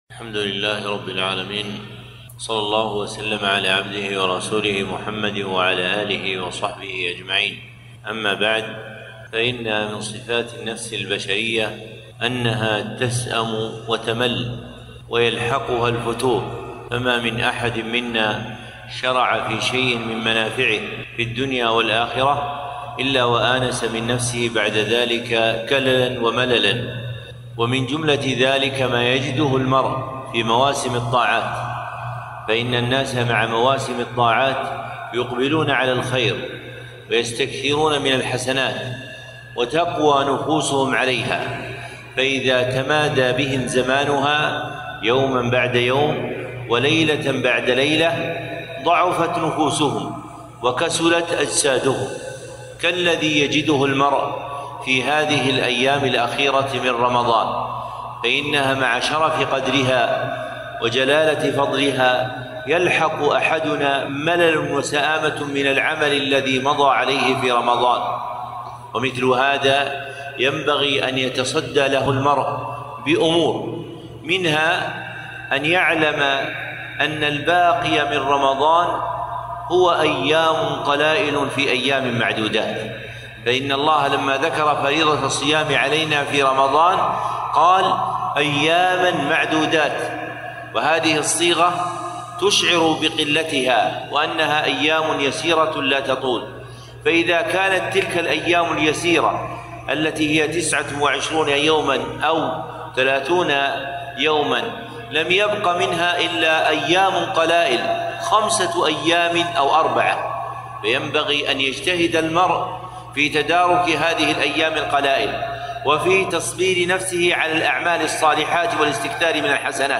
كلمة - تصبير النفس فيما بقي من رمضان